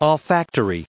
added pronounciation and merriam webster audio
545_olfactory.ogg